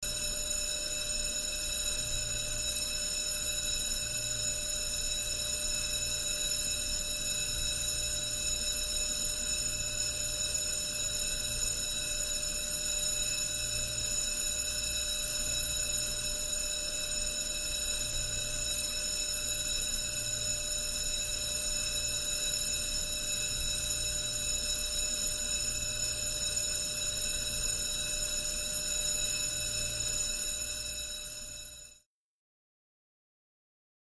Alarm Elevator; Hi Pitched Buzzer Type Steady